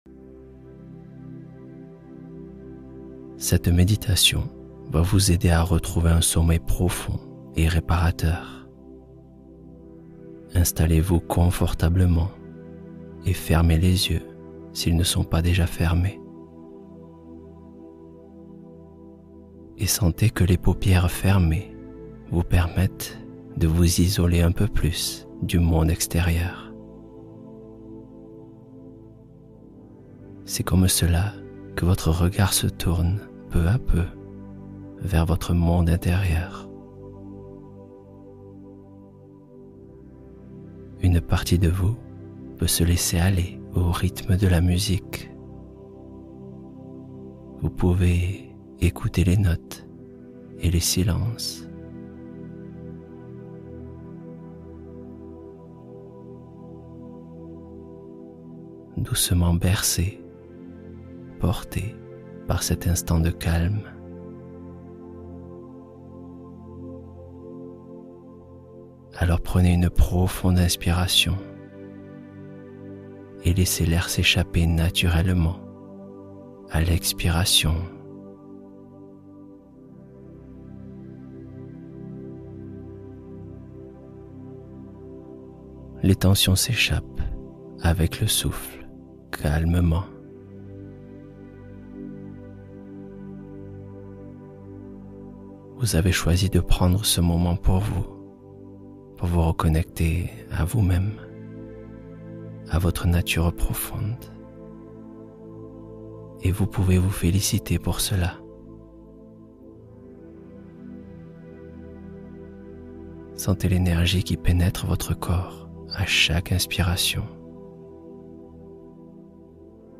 Sommeil paisible : méditation du soir pour se laisser aller